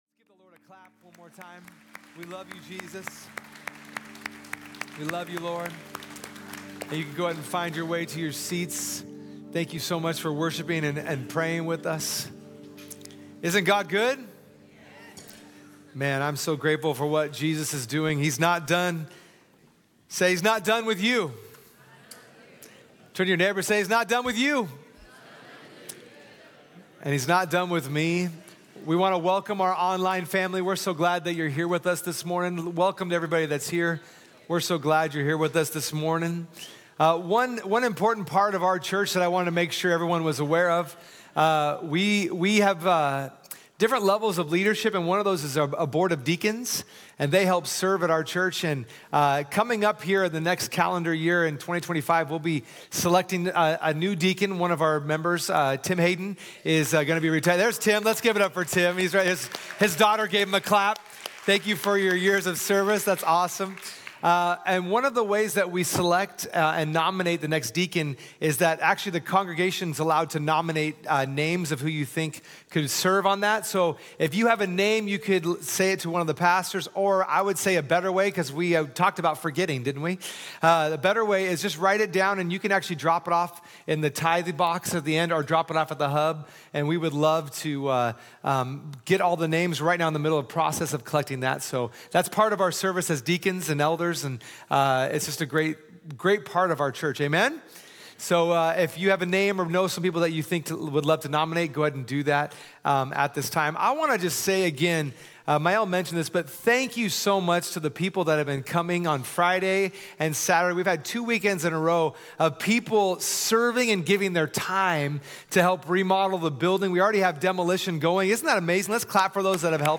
Sunday Messages from Portland Christian Center The Treasure Principle, Part 2 Oct 20 2024 | 00:40:56 1x 00:00 / 00:40:56 Subscribe Share Spotify RSS Feed Share Link Embed